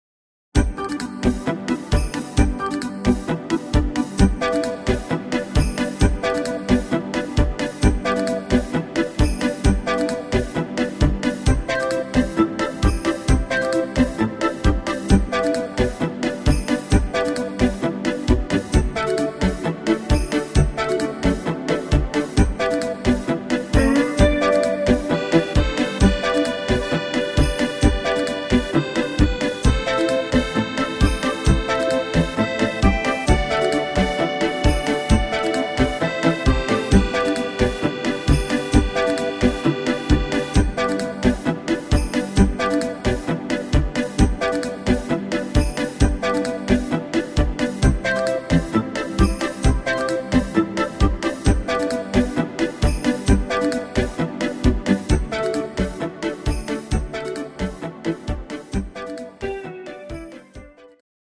Rhythmus  Beguine Swing
Art  Schlager 90er, Deutsch, Oldies